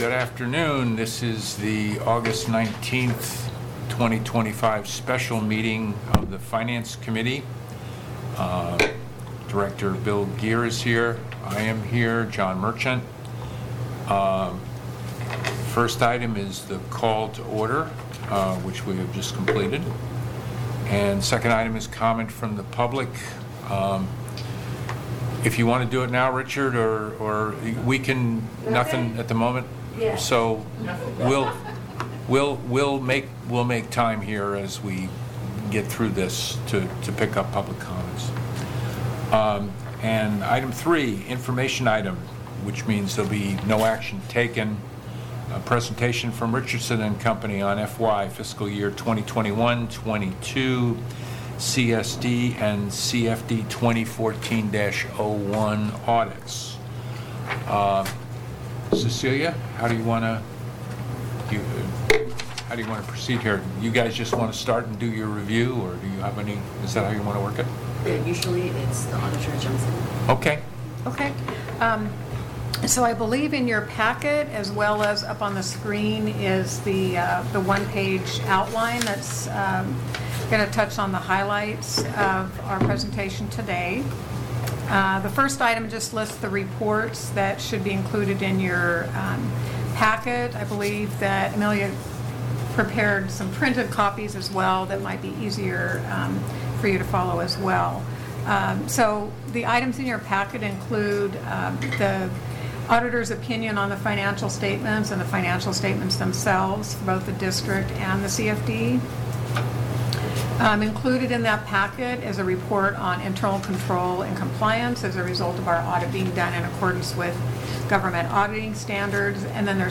Finance Committee Special Meeting at 3:00 p.m.